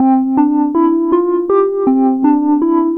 Track 16 - Bells.wav